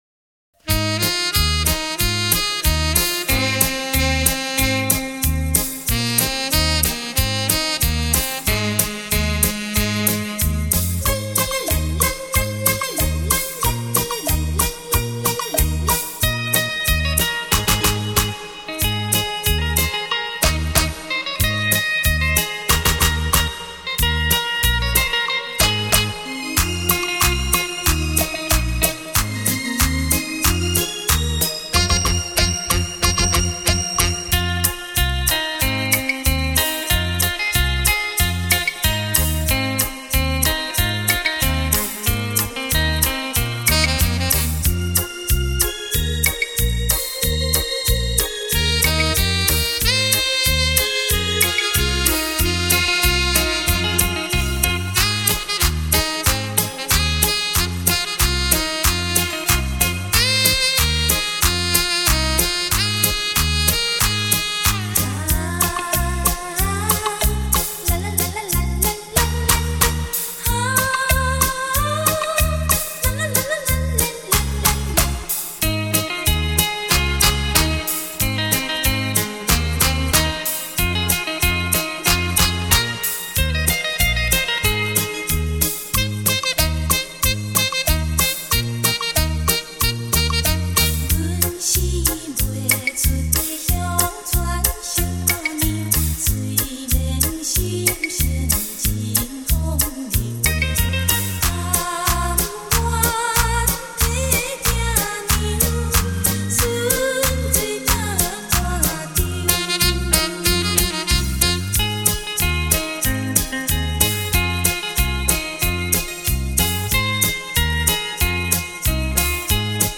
音响测试带